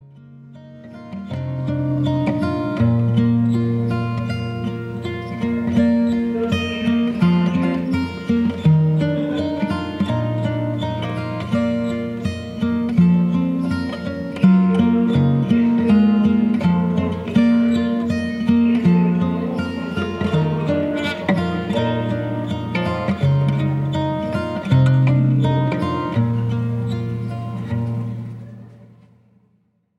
more specifically Jewish Rock.